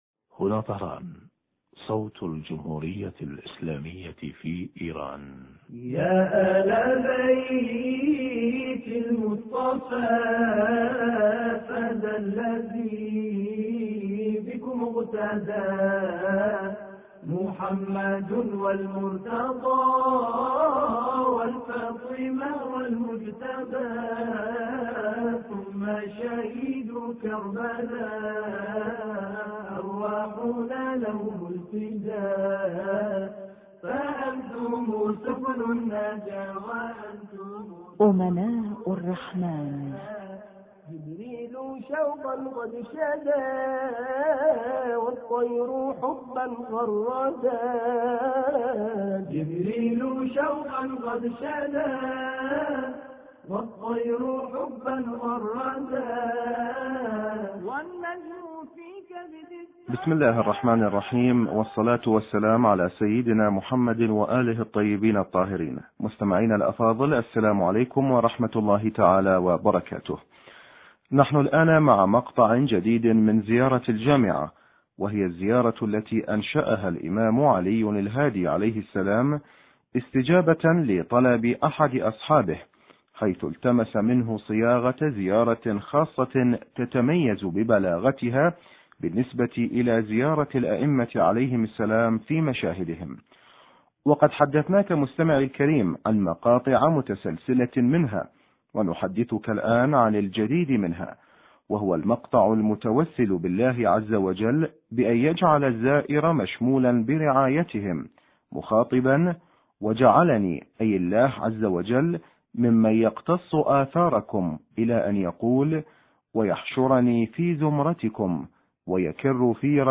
شرح فقرة: ويحشر في زمرتكم ويكر في رجعتكم ويملك في دولتكم... حوار
أما الآن نتابع تقديم برنامج امناء الرحمن بهذا الاتصال الهاتفي